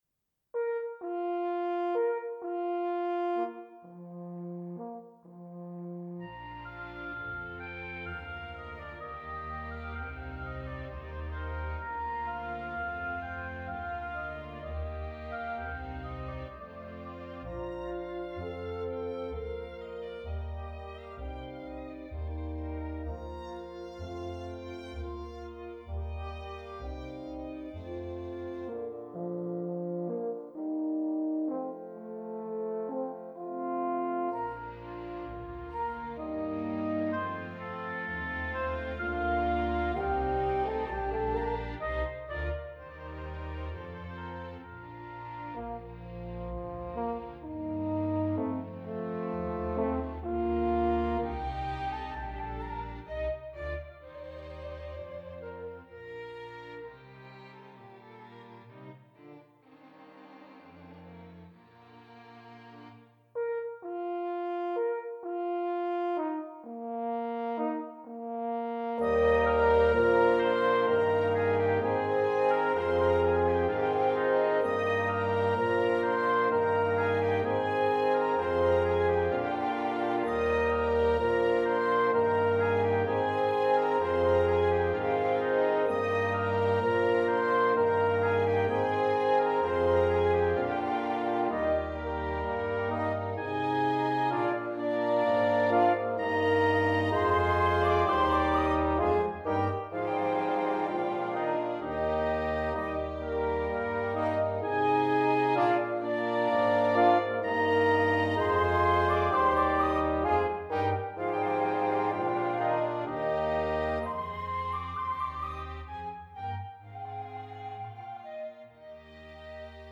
Digital Orchestration
Chamber Orchestra
Instrumentation: Flute, Oboe I/II,
Bassoon I/II, Horn in F I/II, Strings